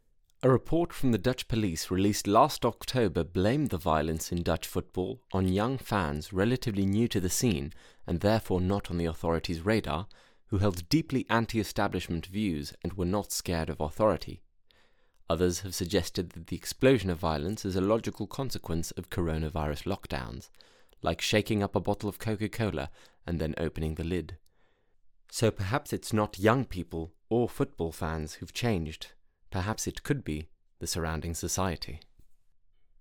Voix off
20 - 42 ans - Baryton